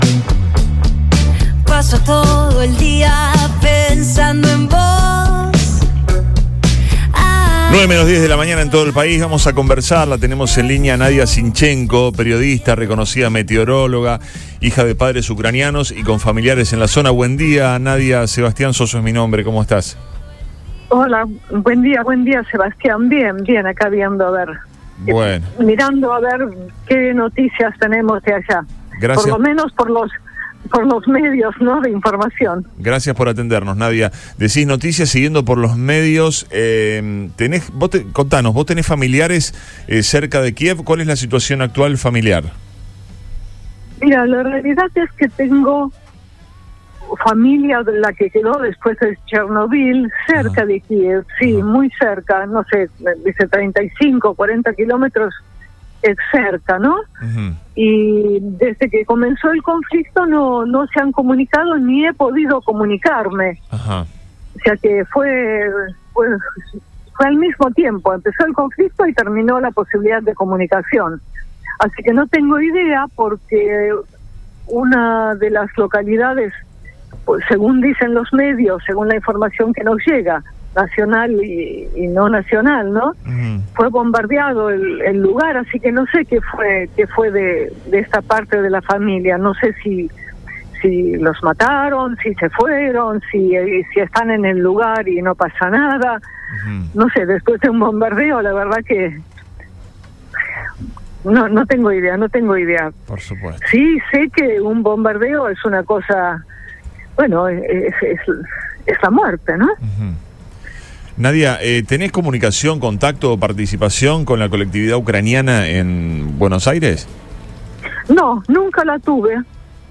En otro tramo de la entrevista contó que no tiene contacto con las asociaciones de ucranianos en Argentina y que se integró completamente con la sociedad argentina.